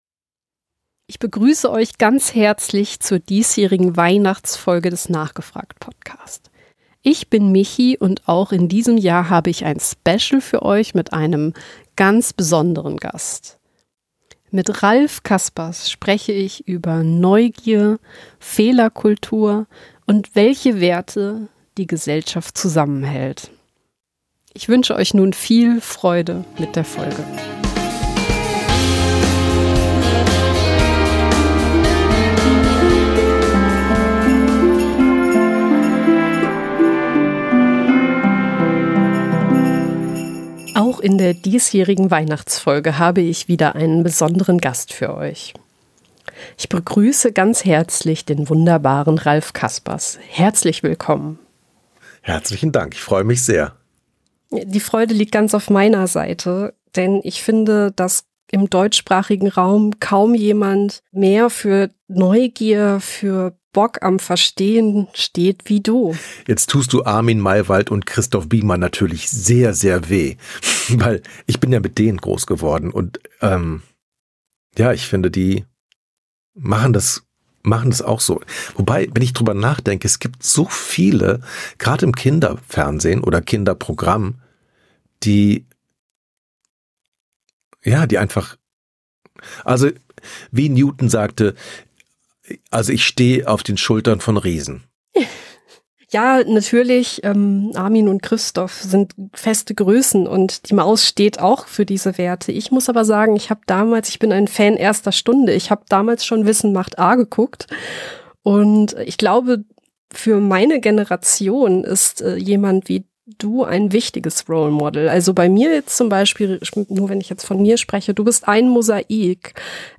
NGF083 – Spezial: Weihnachten, Wissen und warum Neugier verbindet. Ein Gespräch mit Ralph Caspers.